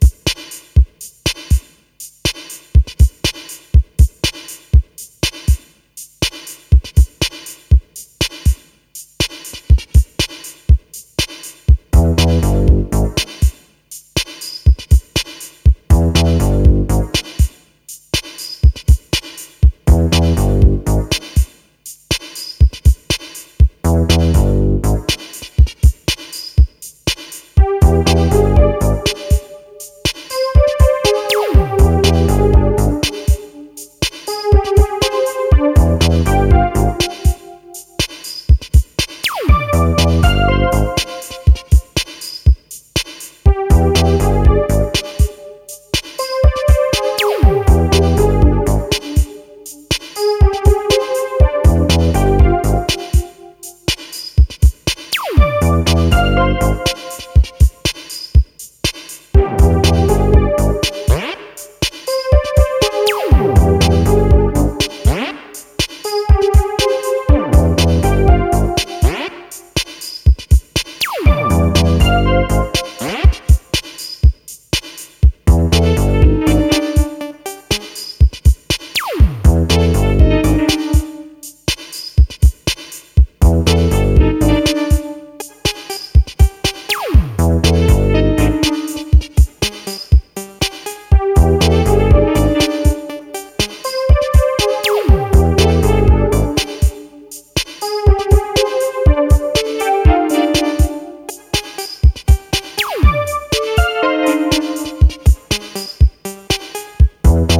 a mix between electro techno disco.